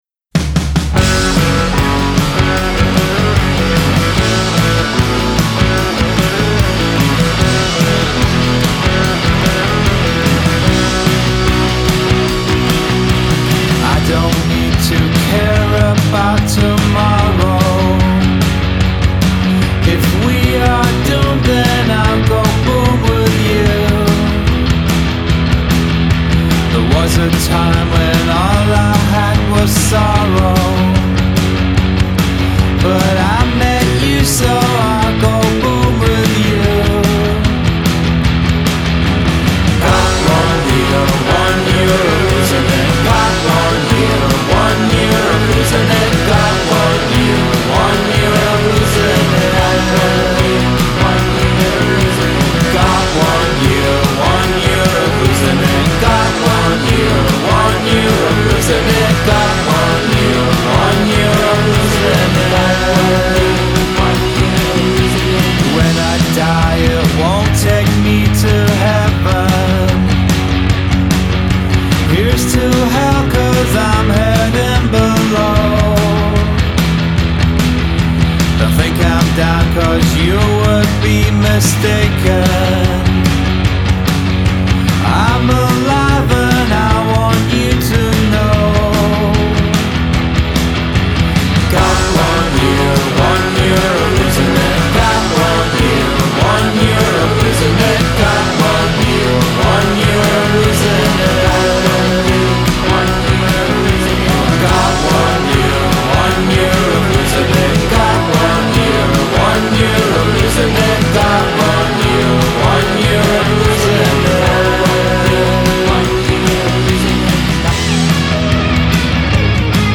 scanzonata